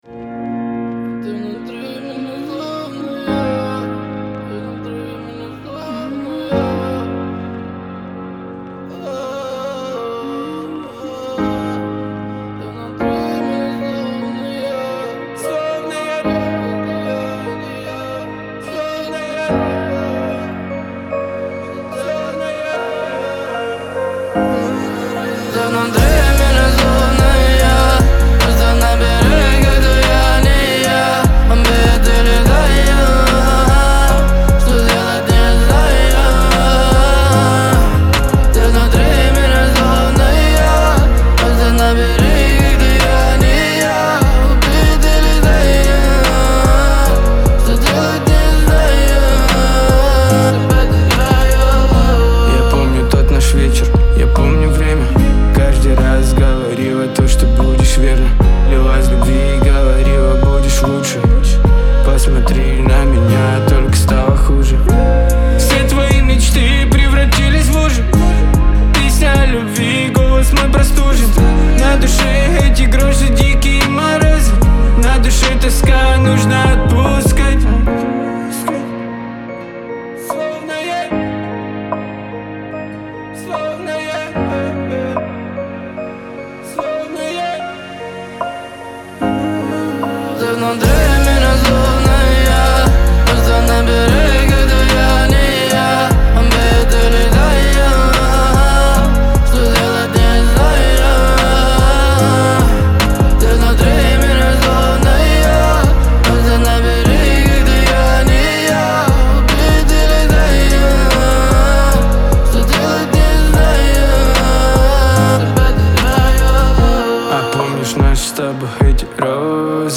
это проникновенная композиция в жанре поп